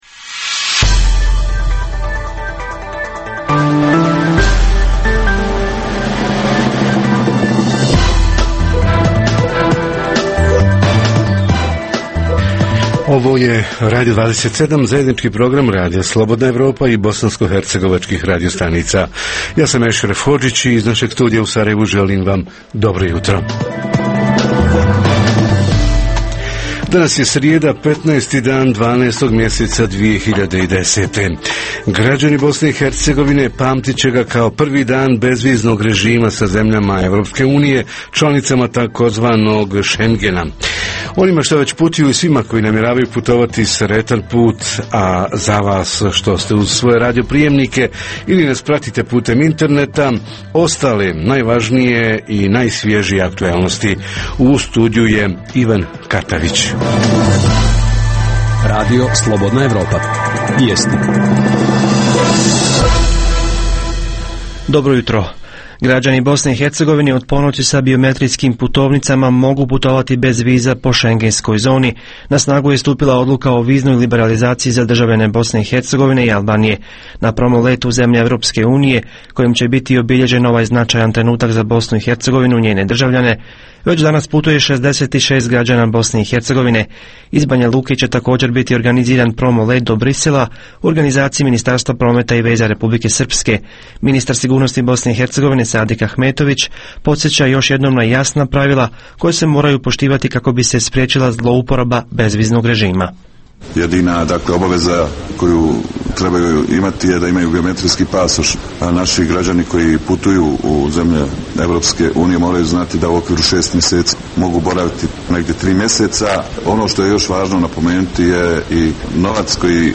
- Ovog jutra tema nam je pomoć ugroženima od poplava – kako i koliko pomažu lokalna vlast, šira zajednica, nevaldine organizacije i građani? - Reporteri iz cijele BiH javljaju o najaktuelnijim događajima u njihovim sredinama.